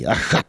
Cri pour chasser le chat ( prononcer le crti )
Catégorie Locution